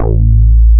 IMAGBASS.wav